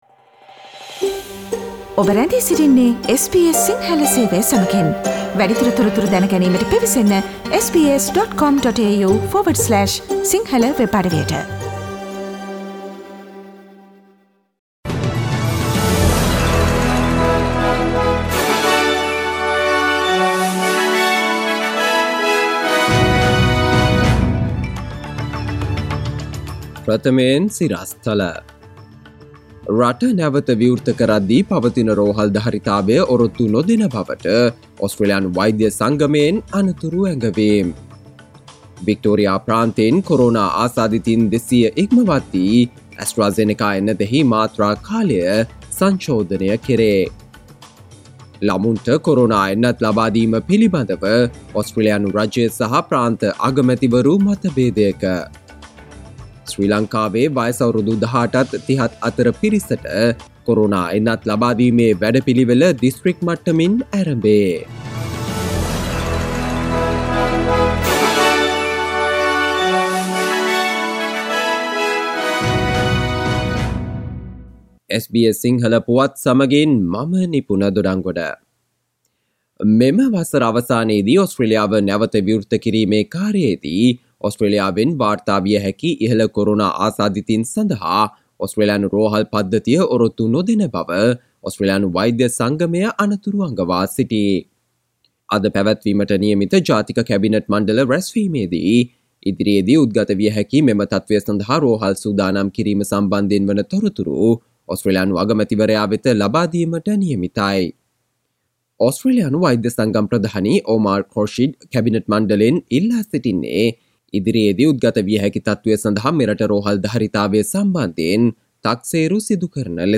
සවන්දෙන්න 2021 සැප්තැම්බර් 03 වන සිකුරාදා SBS සිංහල ගුවන්විදුලියේ ප්‍රවෘත්ති ප්‍රකාශයට...